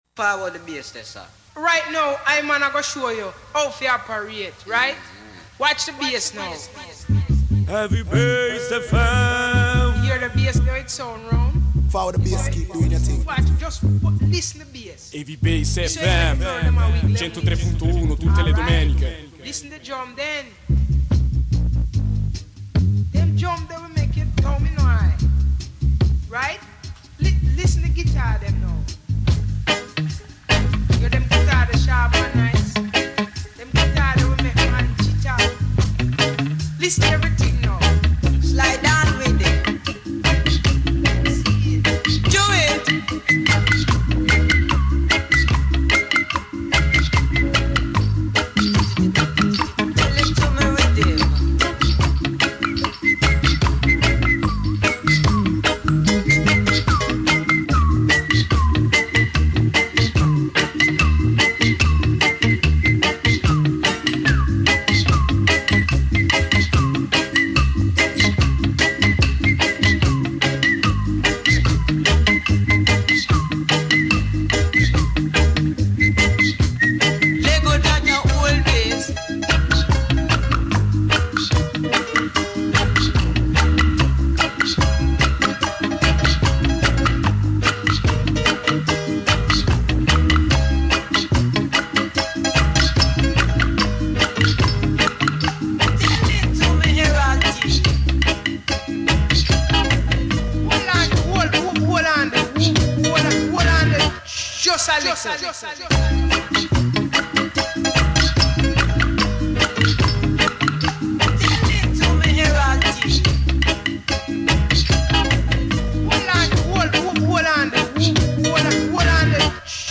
sweet early reggae hits
passing thru the era of the “flying cymbals”
drummer
crucial heavyweight tunes